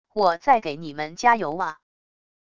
我在给你们加油啊wav音频生成系统WAV Audio Player